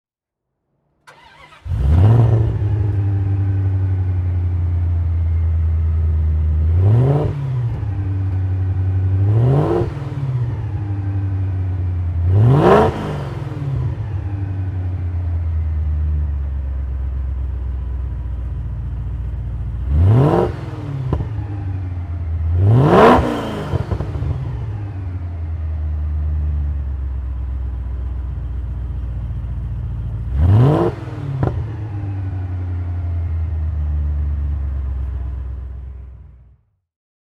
Motorsounds und Tonaufnahmen zu Aston Martin Fahrzeugen (zufällige Auswahl)
Aston Martin V8 Vantage V600 Le Mans (2000) - Starten und Leerlauf